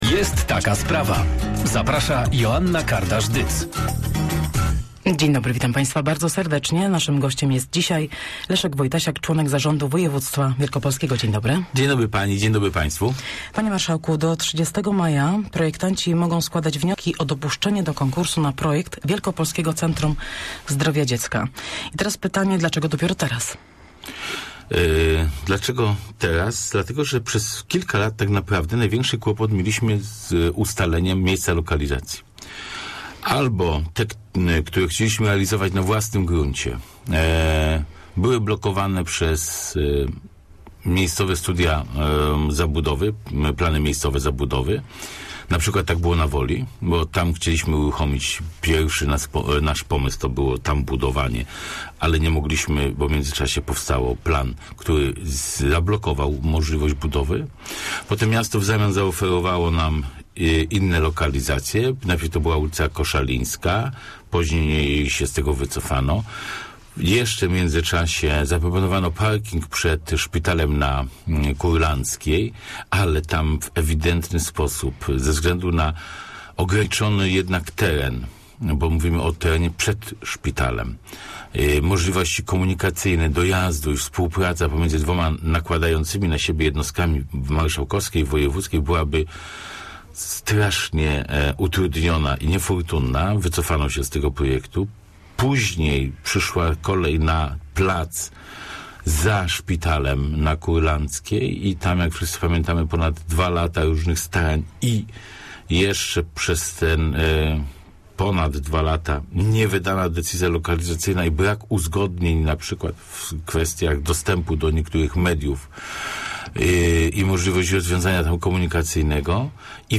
Przetarg na projekt zakończy się 30 maja, a w czerwcu zostanie wyłoniona firma projektowa - potwierdził w porannej rozmowie "Jest taka sprawa" na antenie Radia Merkury członek zarządu województwa wielkopolskiego Leszek Wojtasiak.